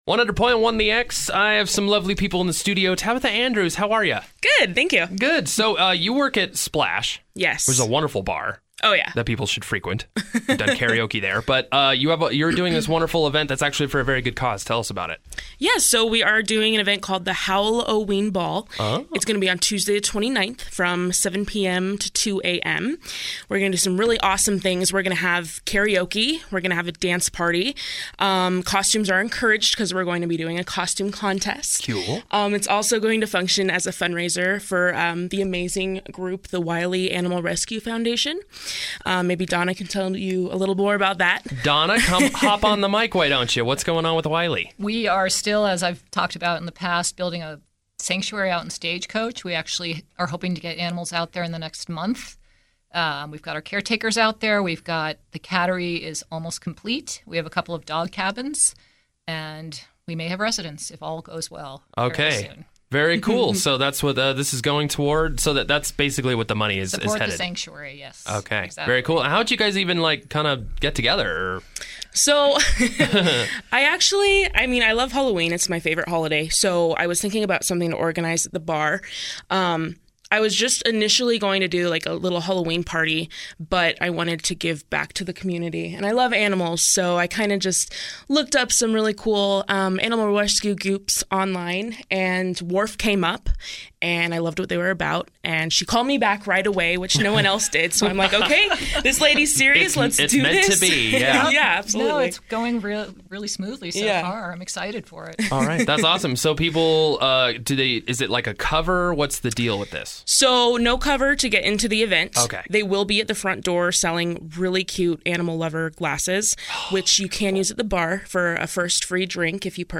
Interview: Howl-o-ween Event